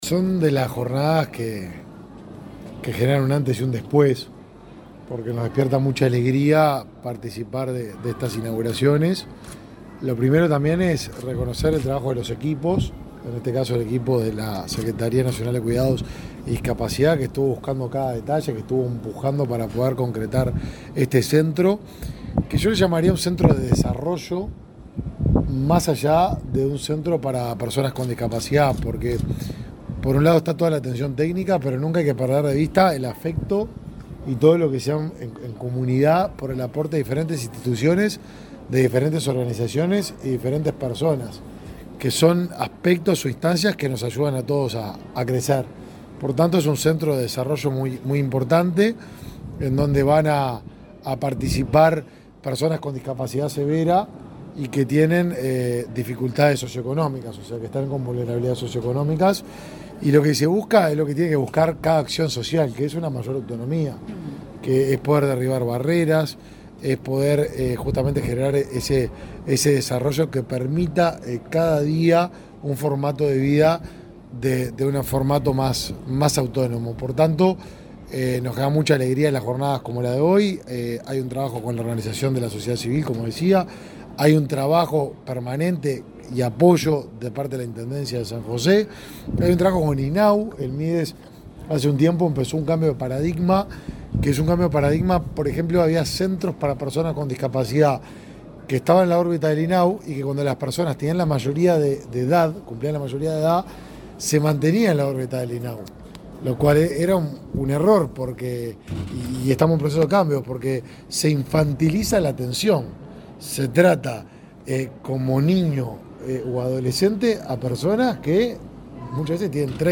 Declaraciones del ministro de Desarrollo Social, Martín Lema
El ministro de Desarrollo Social, Martín Lema, dialogó con la prensa en San José, antes de participar en la inauguración de un centro de alojamiento